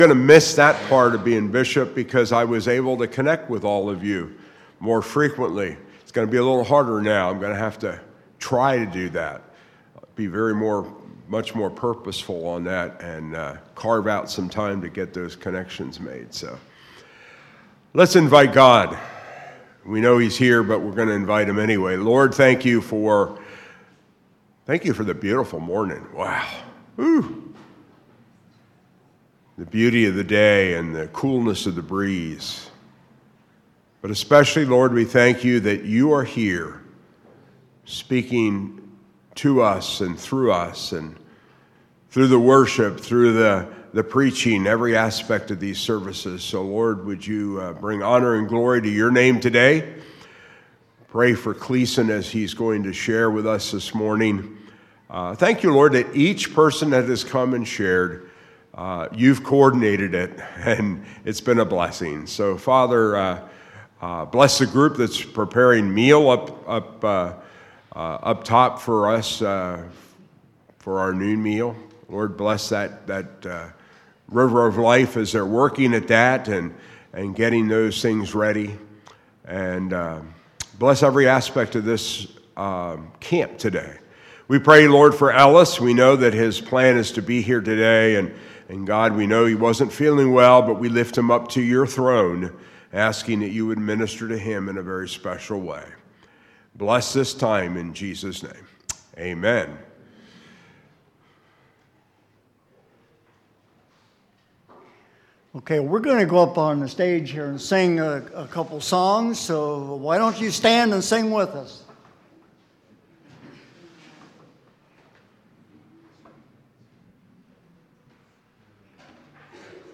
Series: Campmeeting 2025